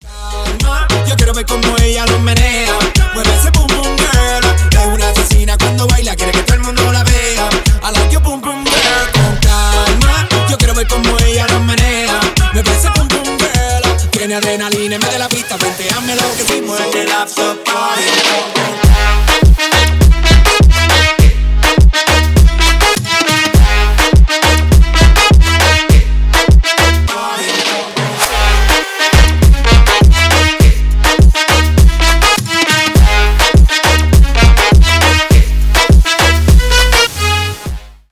Ремикс # Танцевальные
весёлые # латинские